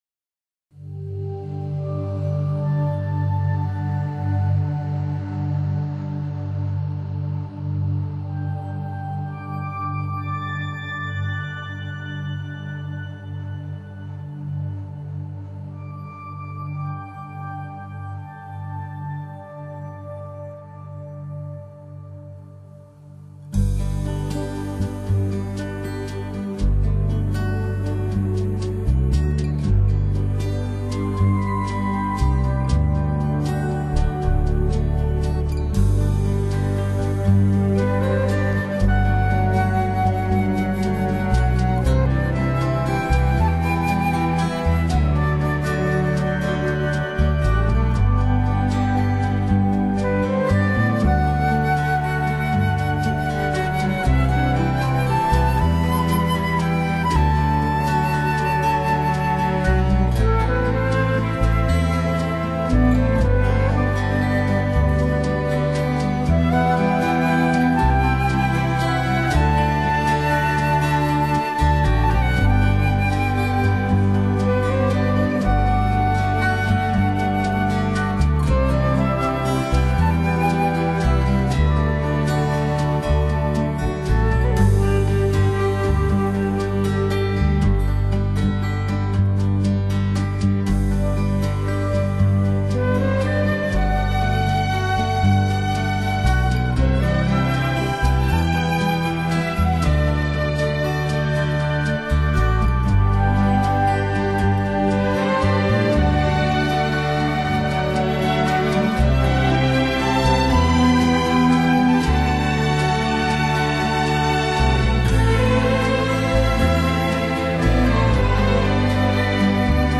开始大提琴低缓的曲调会让你觉得是放错了CD，轻盈的钢琴声随后响起，
苏格兰女孩的悠缓歌声伴随着泥土的清 香讲述着凯而特的传奇！